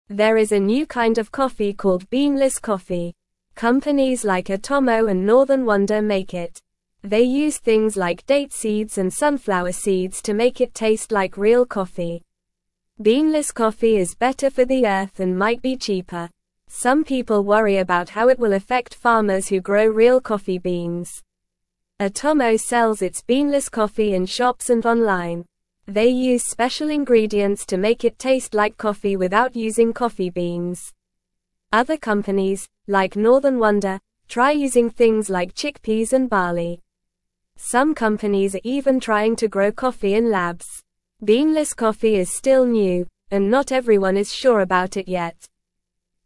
Normal
English-Newsroom-Beginner-NORMAL-Reading-Beanless-Coffee-A-New-Kind-of-Earth-Friendly-Brew.mp3